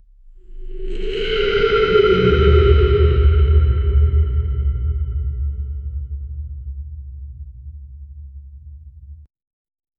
Reverb: 0.3 (Gives it some depth). 0:15 A gunshot—LOUD, reverberating in the small space 0:15 Fiersome yell and shriek of Maa kali a hindu deity fiersome and resonating. 0:10 Monster: guttural, deep croaks reverberating, slimy stretching SFX, mossy wet stomps. 0:10
monster-guttural-deep-cro-jpresiwu.wav